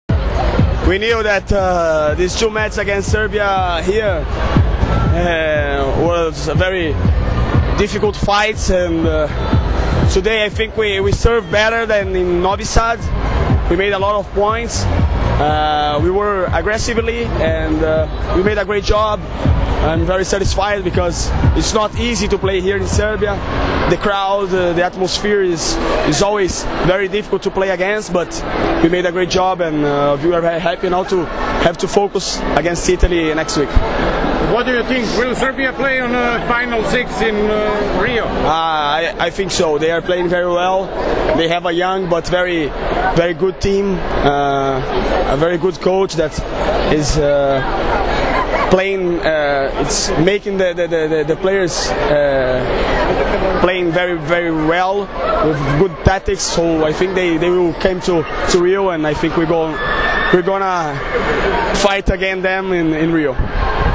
IZJAVA BRUNA REZENDEA